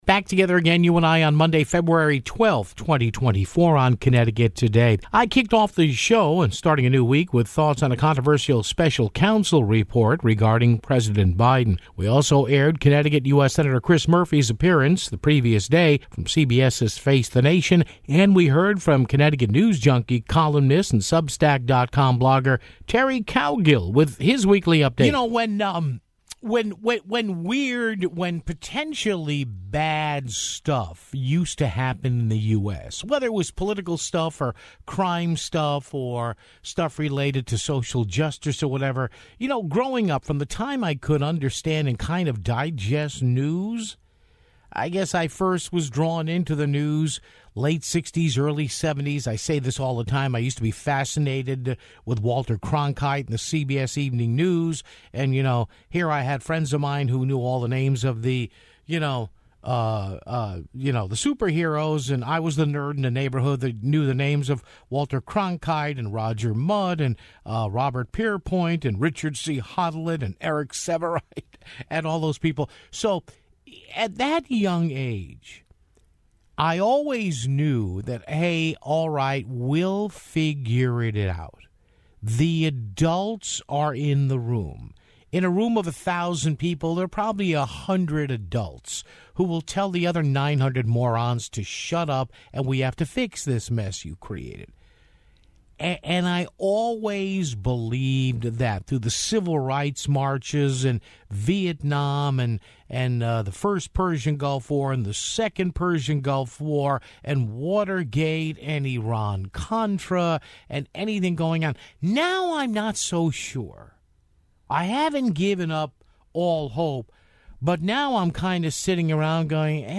We aired Connecticut U.S. Sen. Chris Murphy's appearance the previous day on CBS's "Face the Nation"